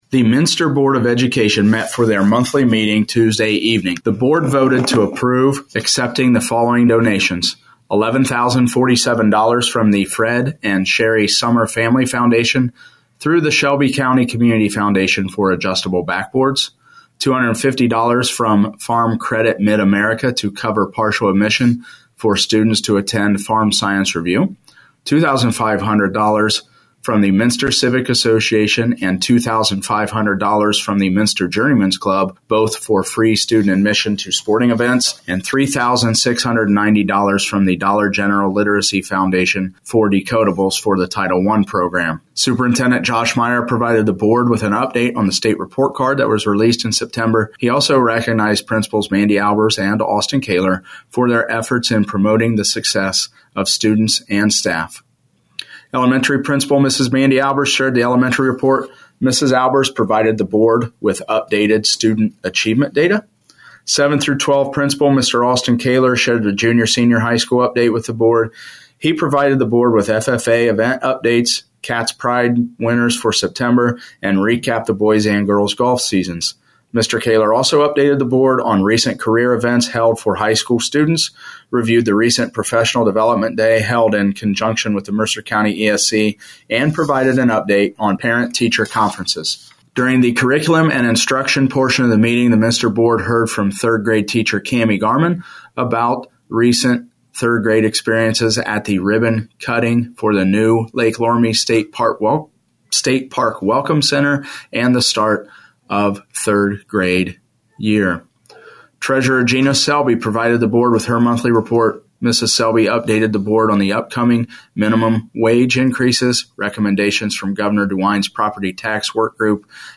To hear an audio recap with Superintendent Josh Meyer: